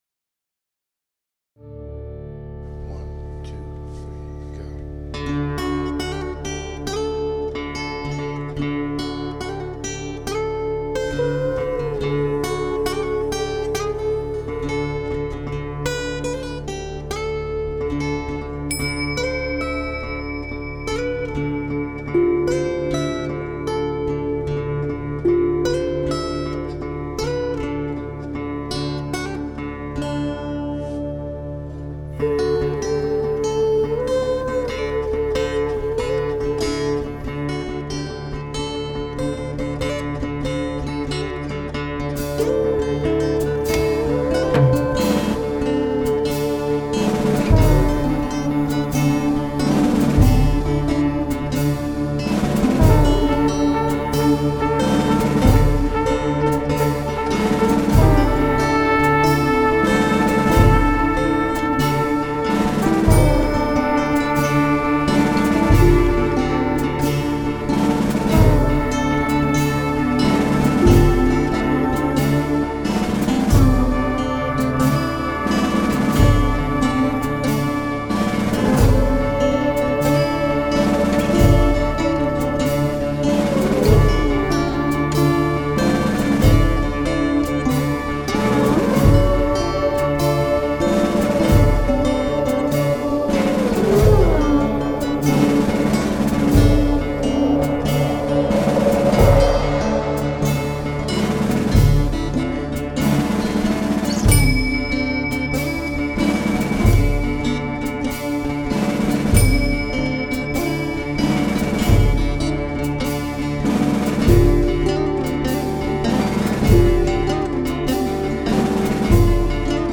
with elements of funk, rock, disco